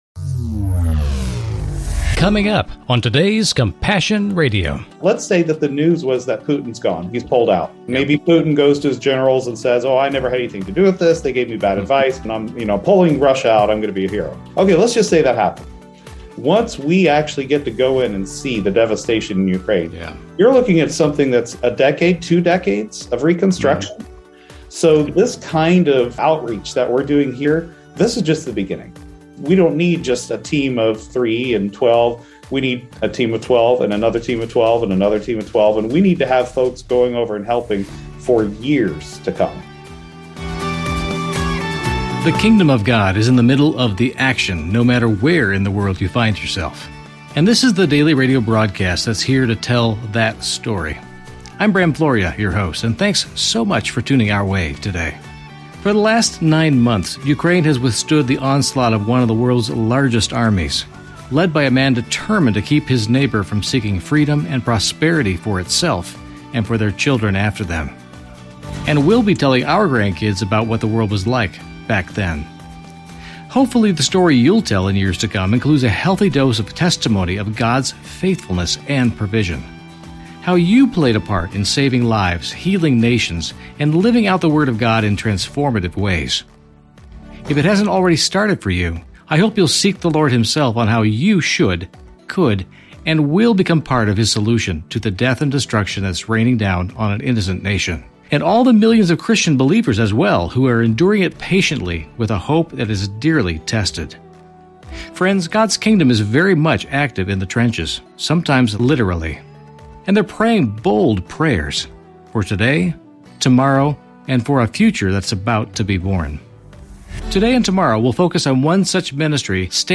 Genre: Christian News Teaching and Talk.